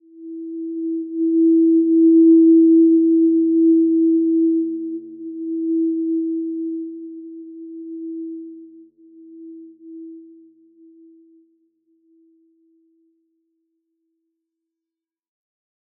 Simple-Glow-E4-mf.wav